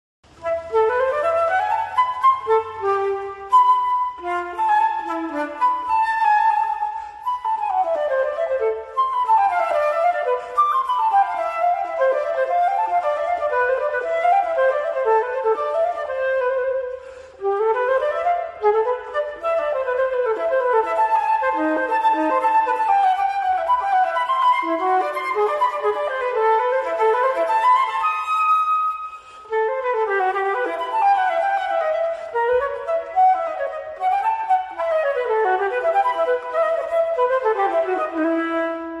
FLAUTA TRAVESERA (viento madera)